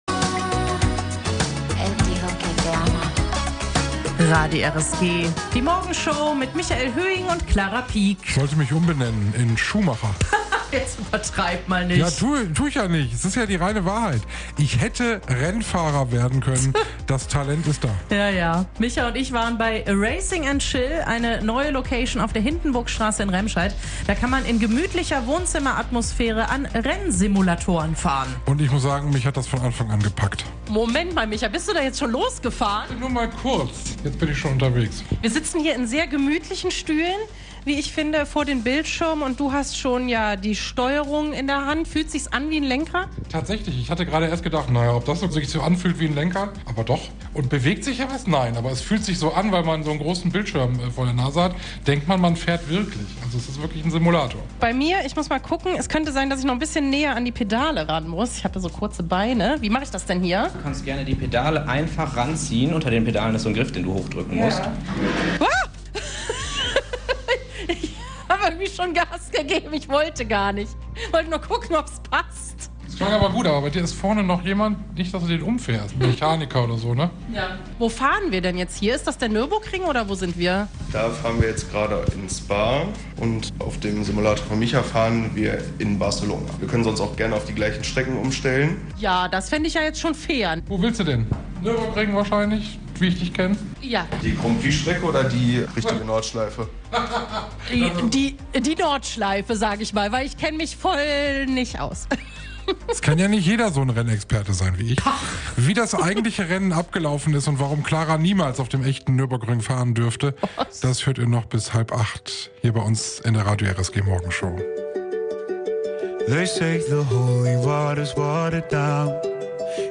Unsere Moderatoren haben die E-Racing Simulatoren für euch getestet.